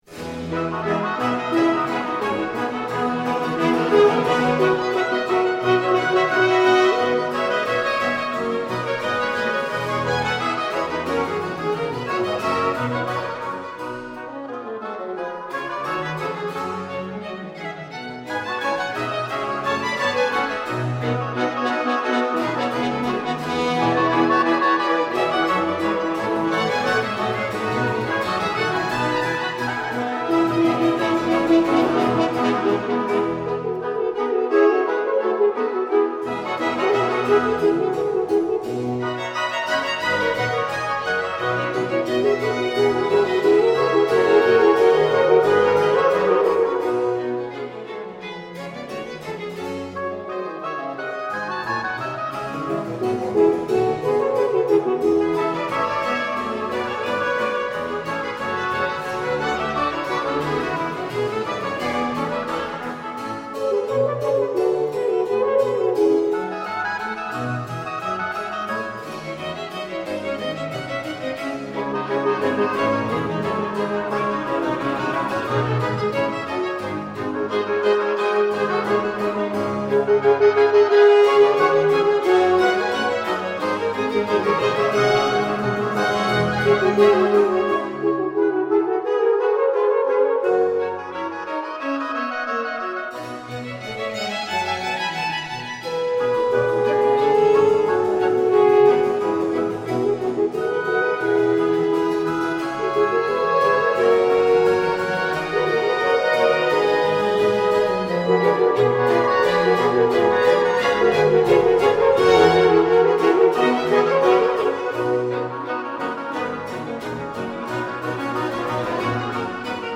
Hay que visionar las fotografías con esta preciosa música barroca,